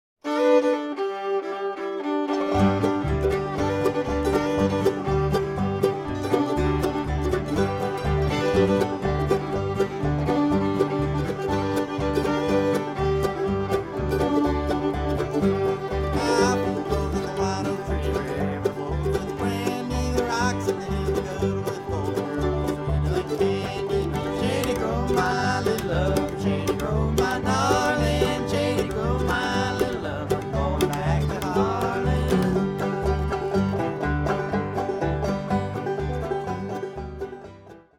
Band version (key of G)